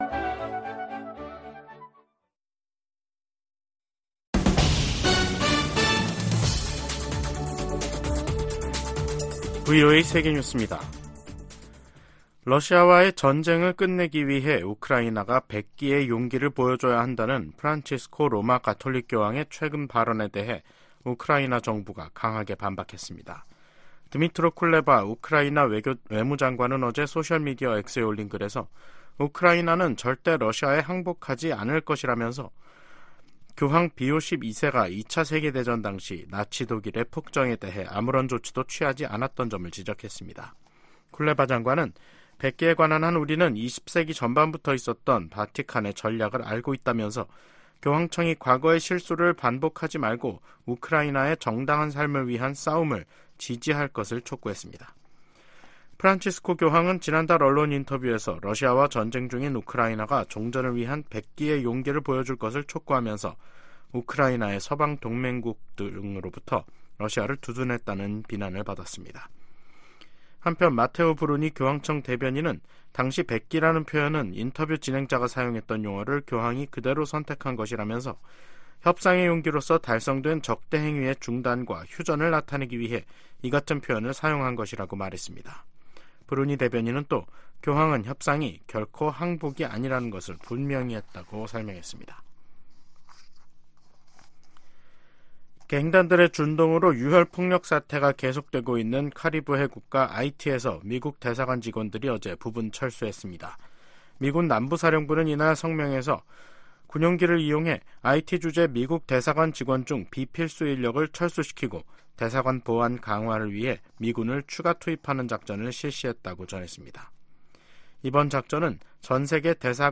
VOA 한국어 간판 뉴스 프로그램 '뉴스 투데이', 2024년 3월 11일 2부 방송입니다. 미국은 전제 조건 없이 대화에 열려 있지만 북한은 관심 징후를 전혀 보이지 않고 있다고 미 국무부가 지적했습니다. 미국과 한국의 북 핵 대표들의 직책 또는 직급이 변화를 맞고 있습니다. 한반도 비핵화 과정의 중간 조치를 고려할 수 있다는 미 당국자들의 언급에 전문가들은 북한 핵 역량 검증의 어려움을 지적했습니다.